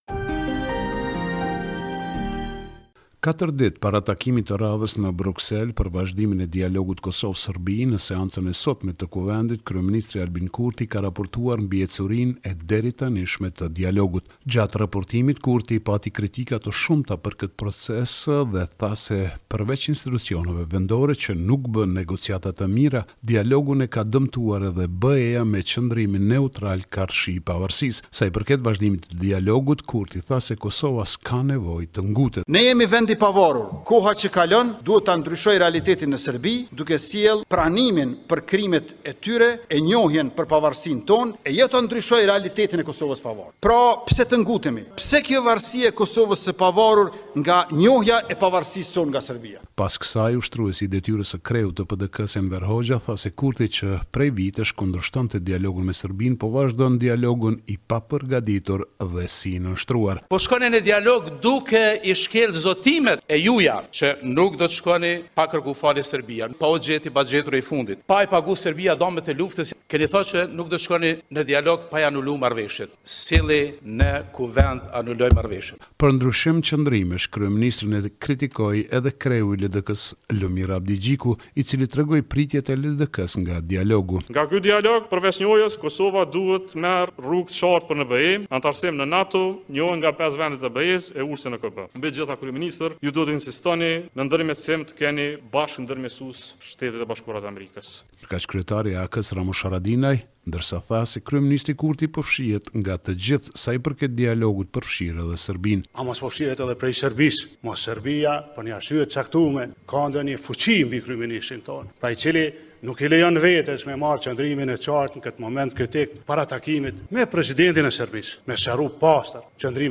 Raporti me te rejat me te fundit nga Kosova.